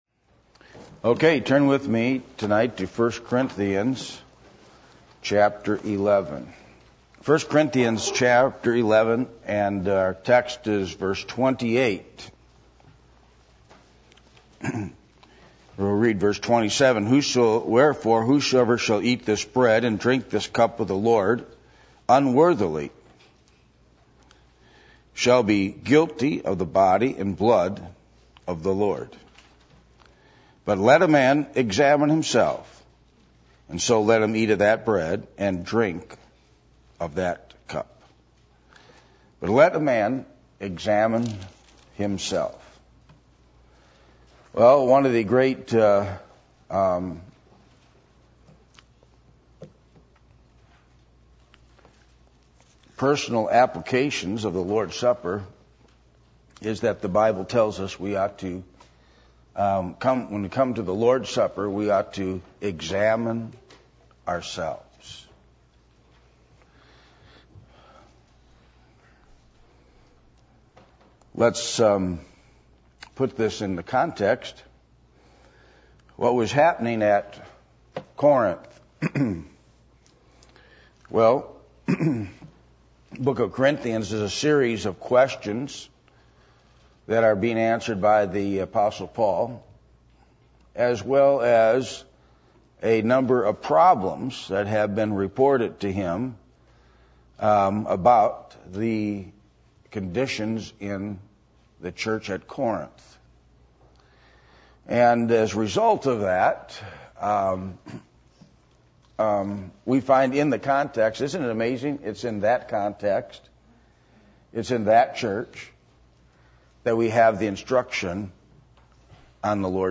Passage: 1 Corinthians 11:27-28 Service Type: Sunday Evening